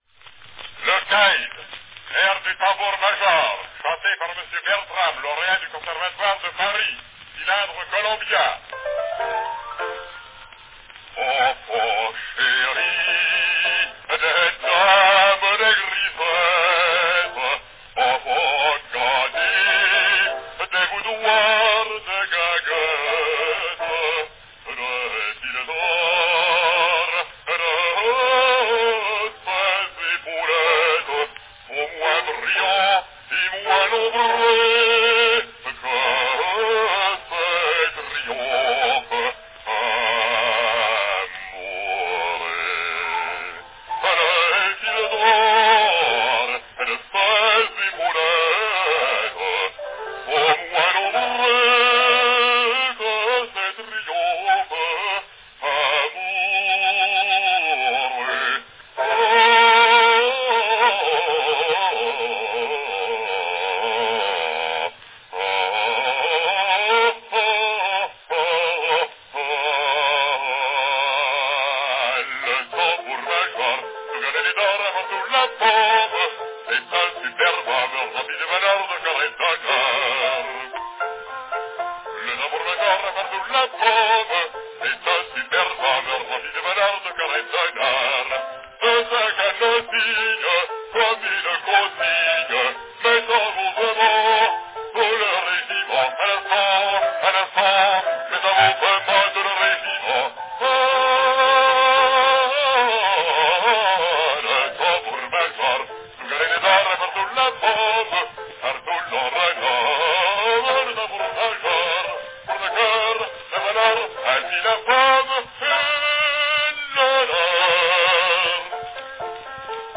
Cylinder of the Month
A rare recording by a somewhat mysterious French bass of the "Drum Major's Air" from the opera Le Caid.
Category Bass
Announcement "Le Caïd